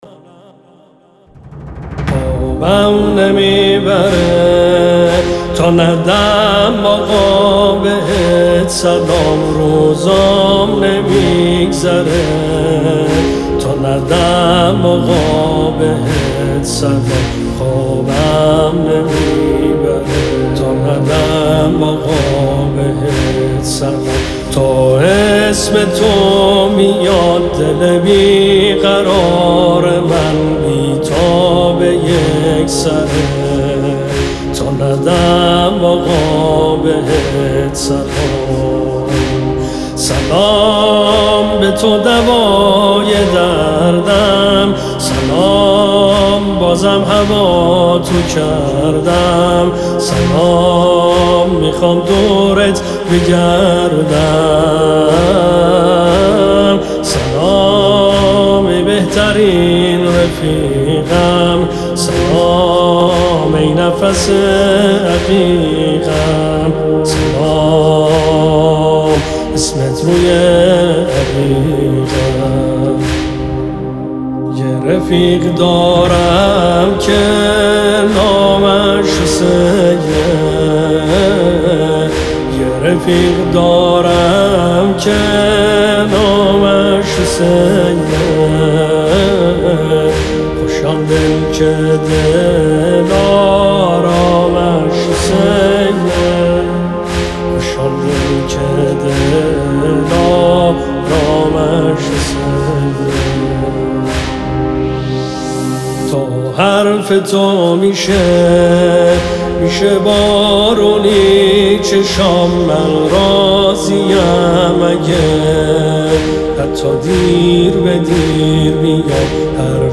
مداحی